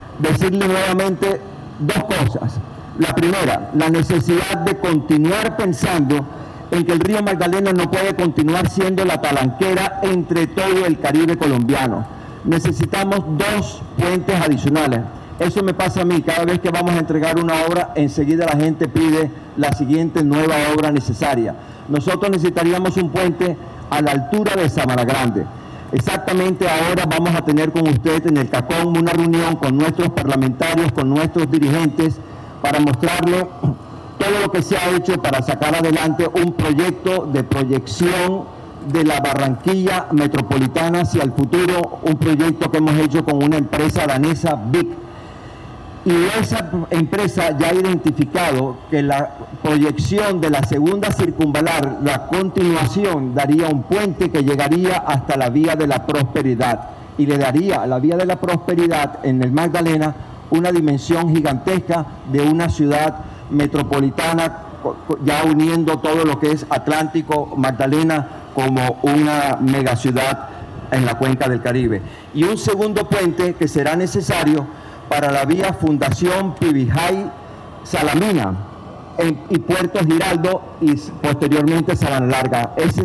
Durante el acto, Verano pidió al alto mandatario que continúe pensando en el que el río Magdalena no puede seguir siendo la talanquera del Caribe colombiano, y por ello es necesario que hagan dos puentes adicionales, fundamentales para el Atlántico, un puente a la altura de Sabanagrande y el segundo puente para la vía que conecta a Fundación, Pivijai, Salamina y Puerto Giraldo.
AUDIO-GOBERNADOR-online-audio-converter.com_.mp3